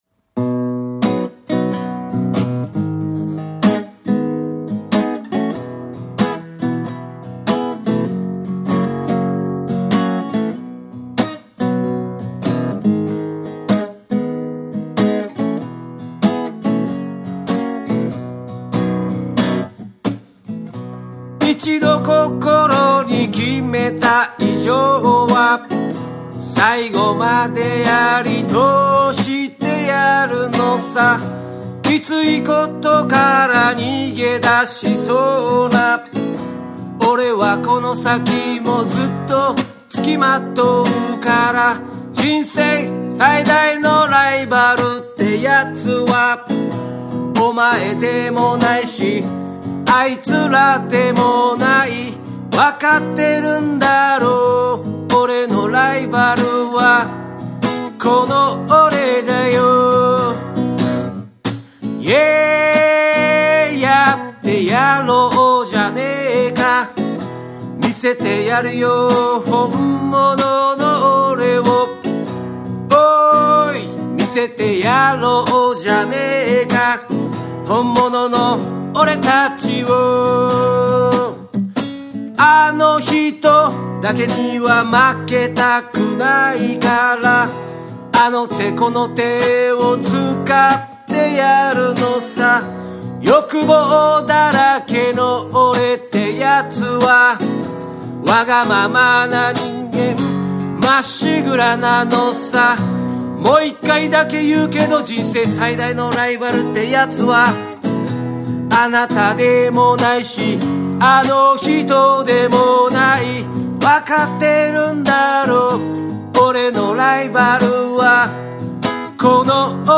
横浜・月桃荘で録音されたこの楽曲は、シンプルながら胸に響く力強い仕上がりに！
チヂン太鼓の独特なリズムと島唄の歌声が、徐々にDubbyでReggaeでJazzyな世界観へと展開していく。
JAPANESE / REGGAE & DUB / NEW RELEASE(新譜)